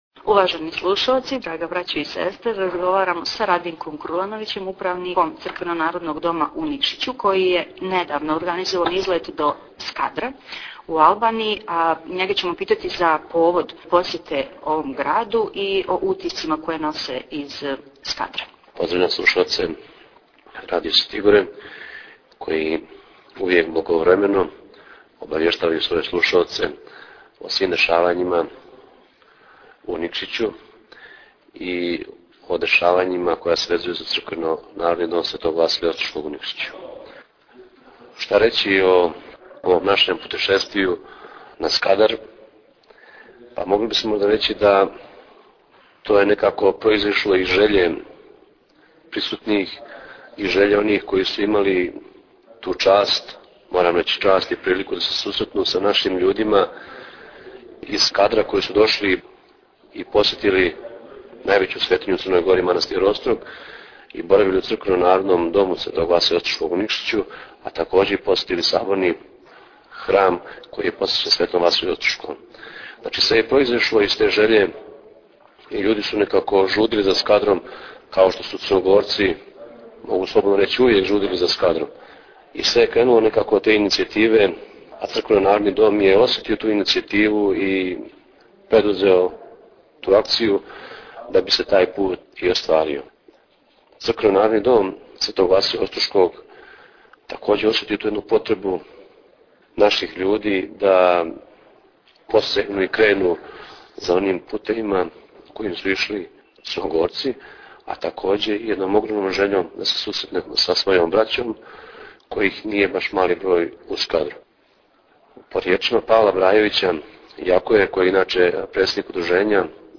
Посјета Скадру и Враки Tagged: Актуелни разговори